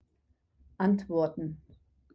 to answer antworten (ANT-wor-ten)